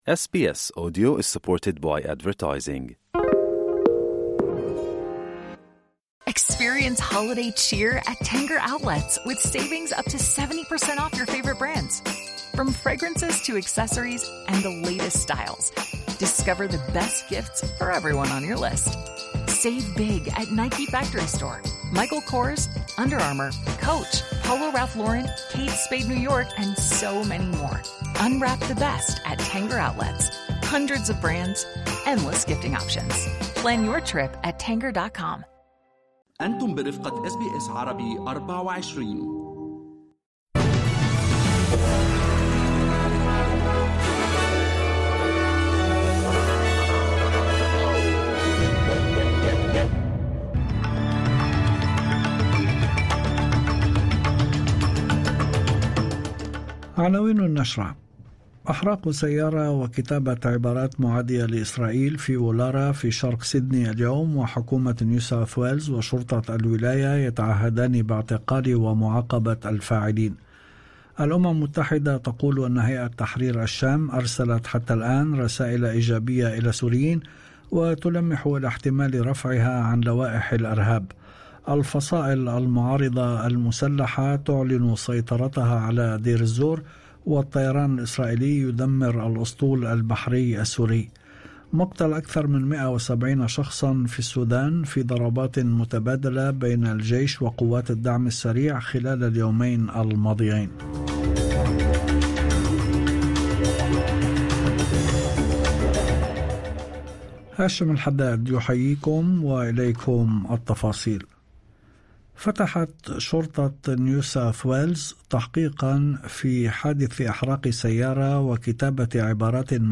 نشرة أخبار المساء 11/12/2024